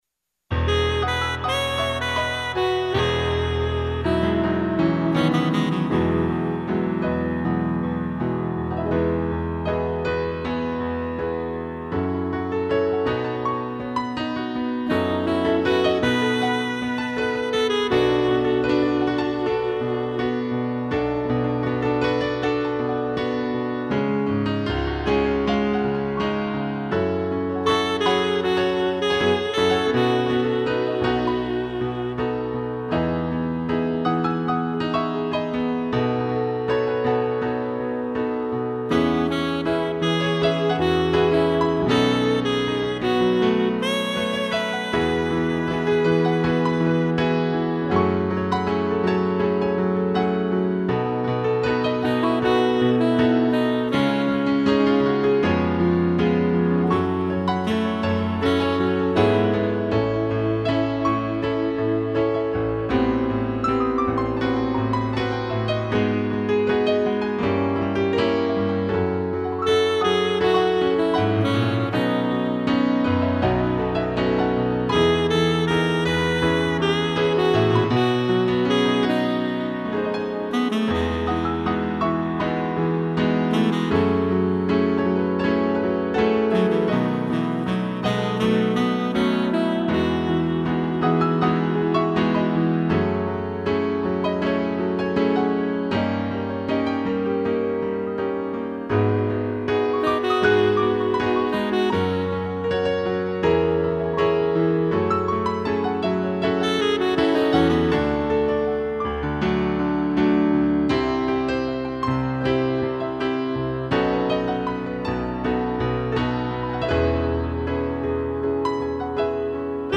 2 pianos e sax
(instrumental)